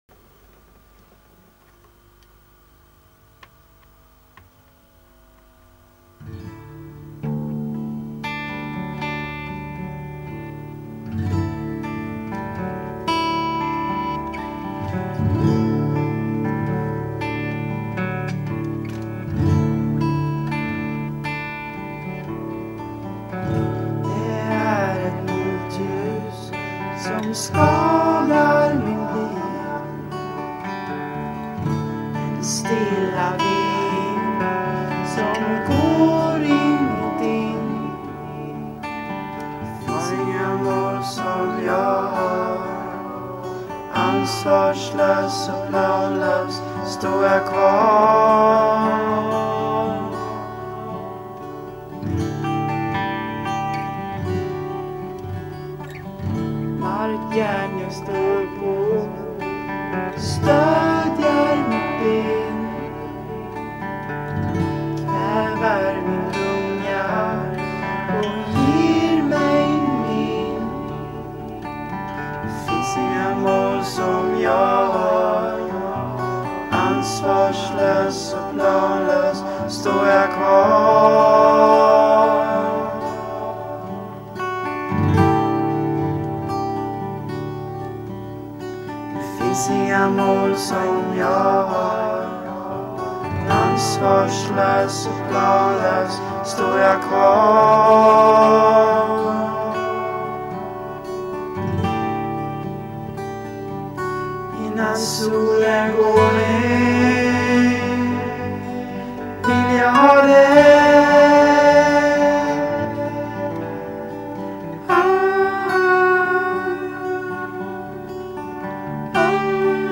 Är ett svenskt popband som sjunger låtar på svenska.